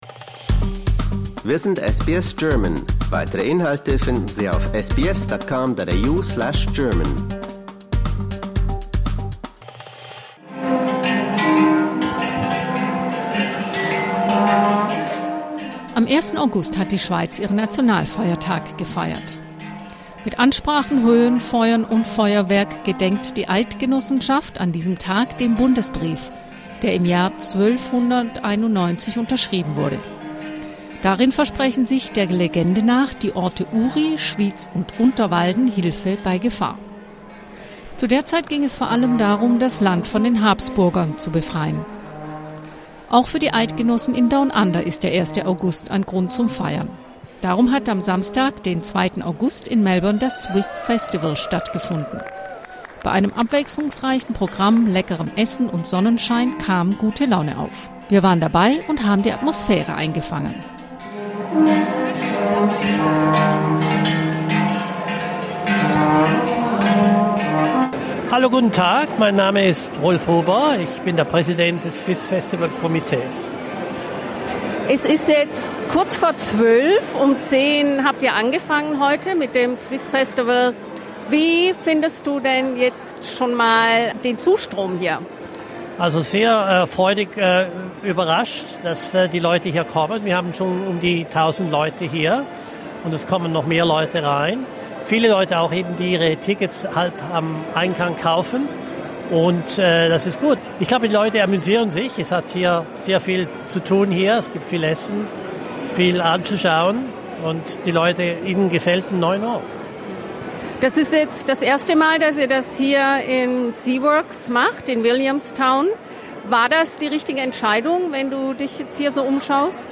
Das ist die Bilanz des Swiss Festivals, das am 2. August in Melbourne stattgefunden hat. Wir waren dabei und haben die Atmosphäre eingefangen.